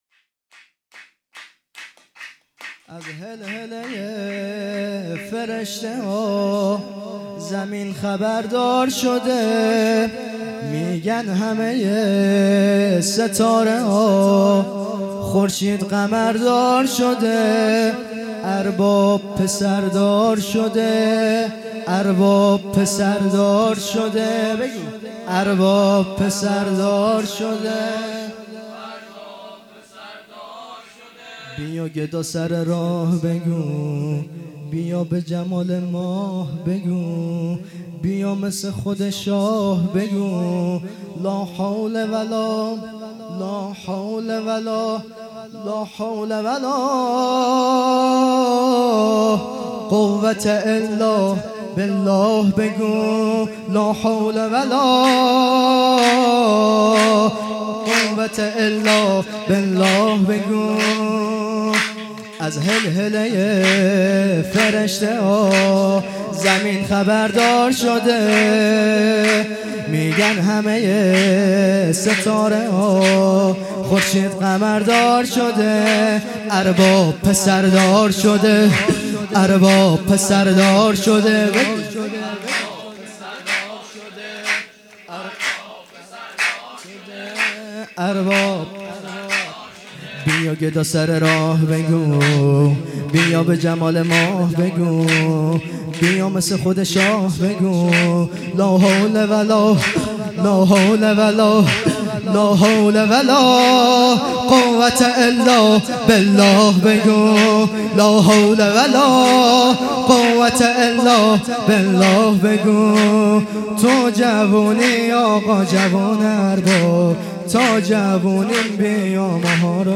جشن ولادت حضرت علی اکبر علیه السلام